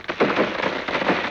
Index of /90_sSampleCDs/E-MU Producer Series Vol. 3 – Hollywood Sound Effects/Water/Falling Branches
LIMB CRAC04L.wav